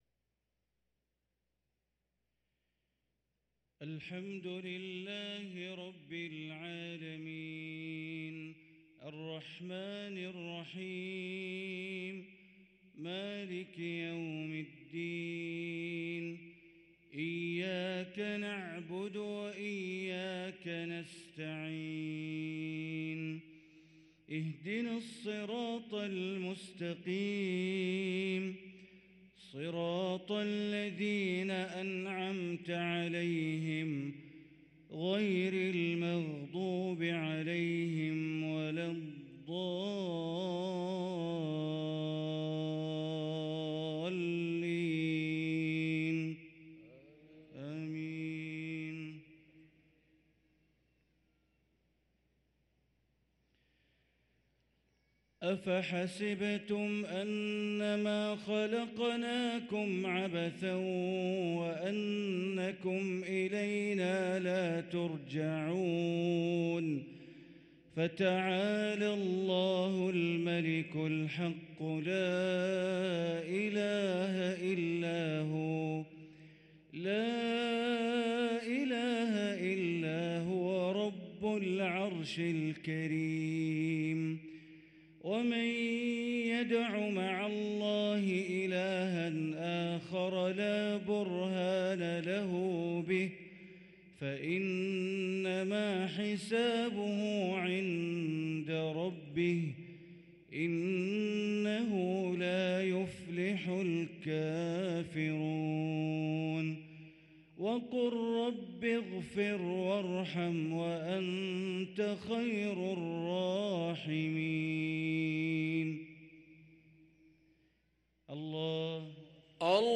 صلاة المغرب للقارئ بندر بليلة 27 جمادي الآخر 1444 هـ
تِلَاوَات الْحَرَمَيْن .